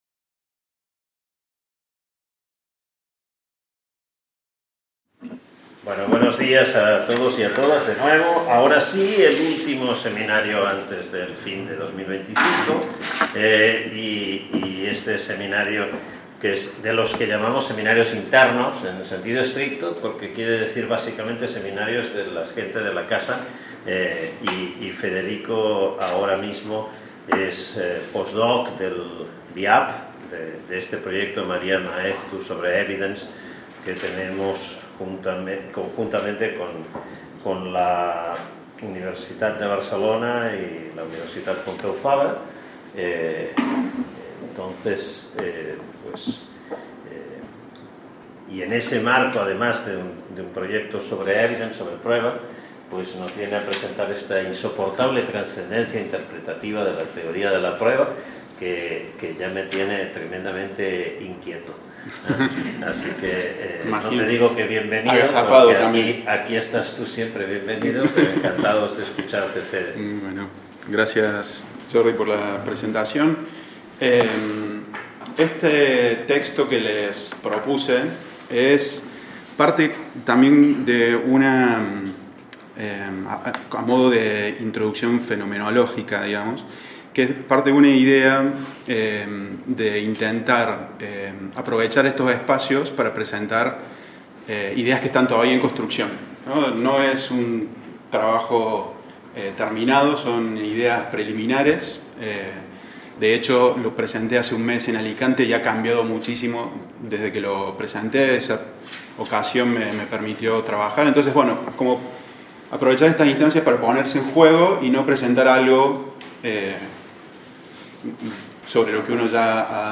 The Chair of Legal Culture and the Research Group on Philosophy of Law organize the seminar on the theory of evidence and its impact on the interpretation of the content of legal norms